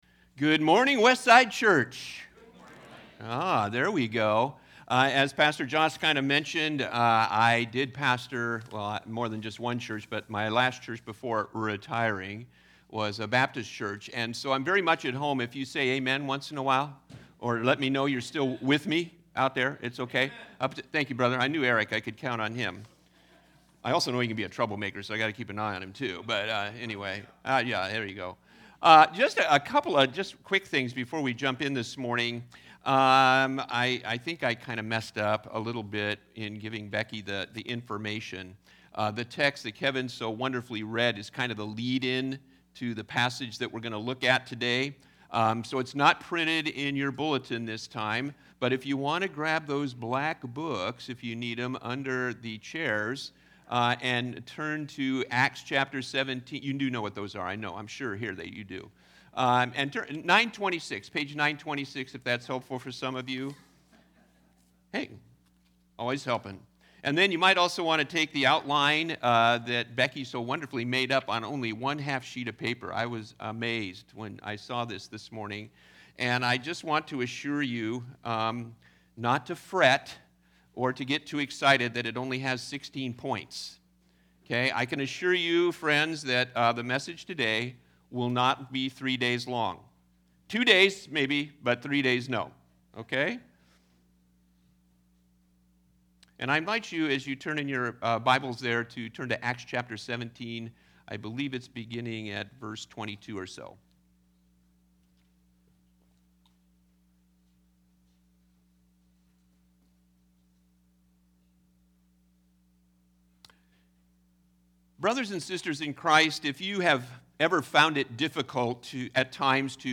Acts 17:16-34 Service Type: Special Sermons The Big Idea